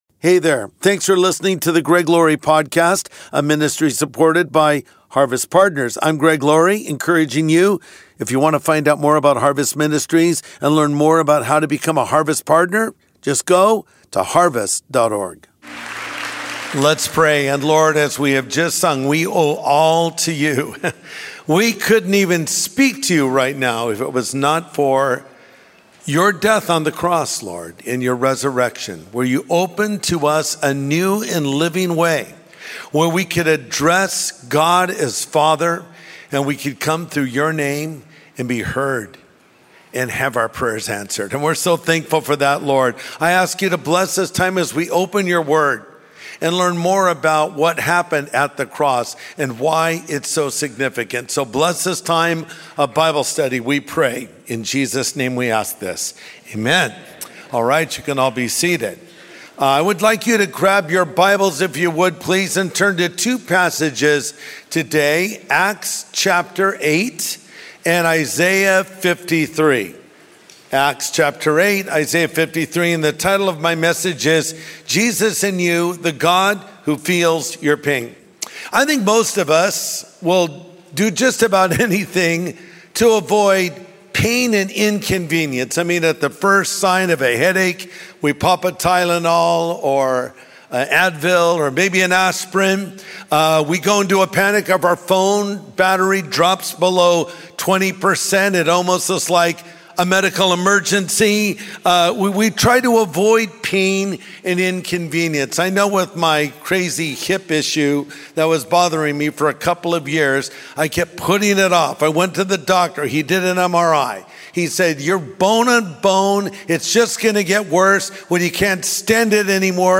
The God Who Feels Your Pain | Sunday Message